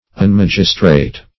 Search Result for " unmagistrate" : The Collaborative International Dictionary of English v.0.48: Unmagistrate \Un*mag"is*trate\, v. t. [1st pref. un- + magistrate.] To divest of the office or authority of a magistrate.
unmagistrate.mp3